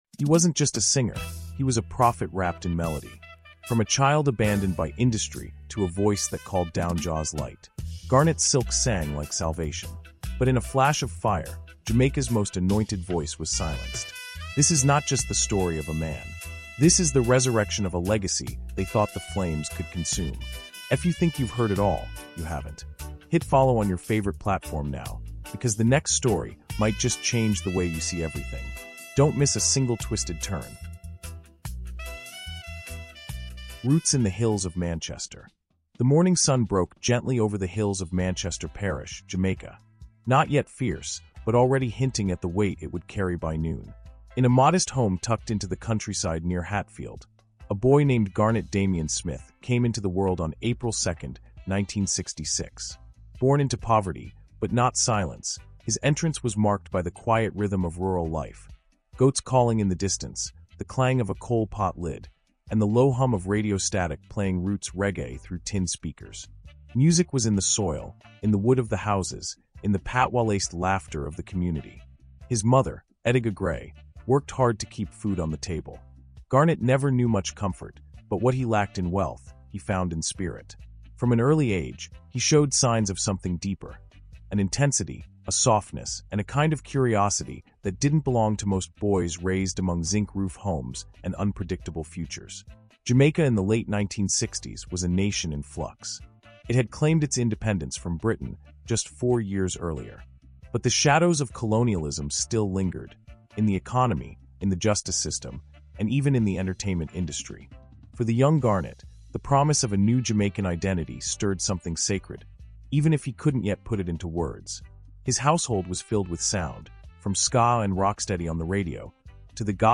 CARIBBEAN HISTORY: Garnet Silk — The Voice That Rose From the Ashes is a powerful, emotionally immersive audiobook documentary that explores the extraordinary life, legacy, and tragic end of one of Jamaica’s most beloved reggae prophets. From his early roots in Manchester as a young deejay called “Little Bimbo,” to his spiritual transformation into Garnet Silk — this historical deep-dive captures the journey of a man who used